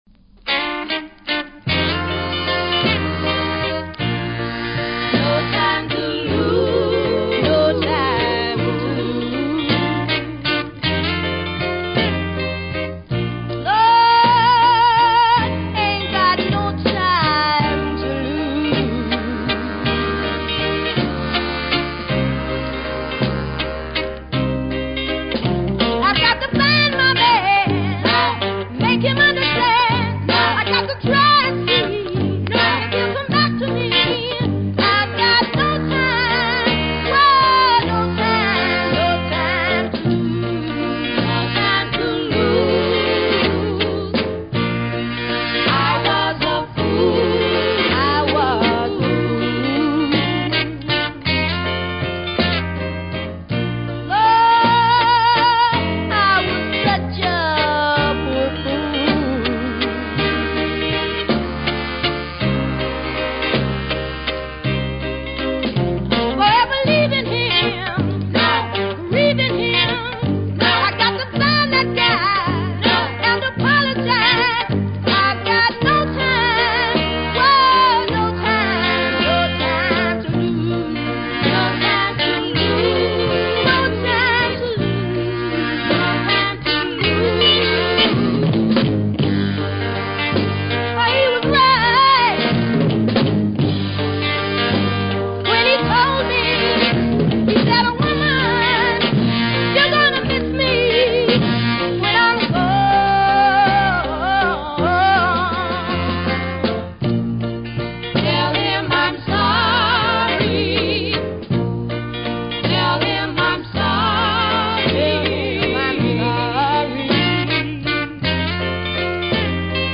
VINTAGE SOUL
60'S FEMALE